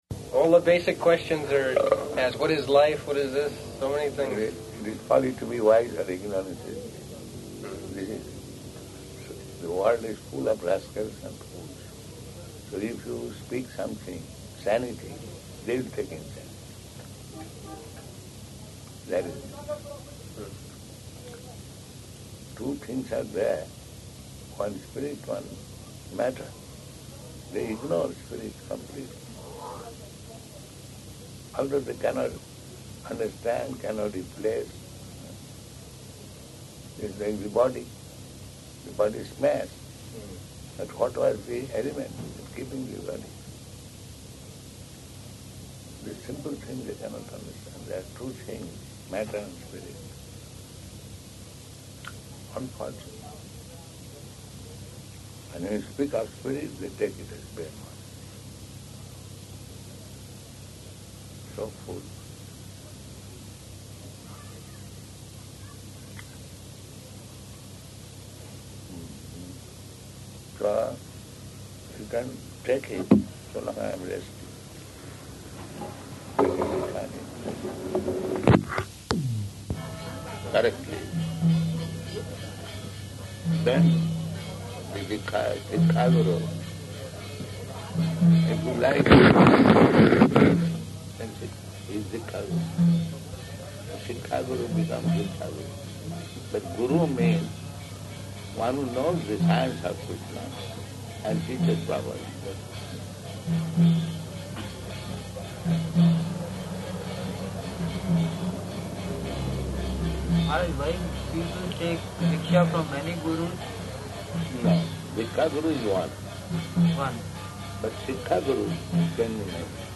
Room Conversation
-- Type: Conversation Dated: January 31st 1977 Location: Bhubaneswar Audio file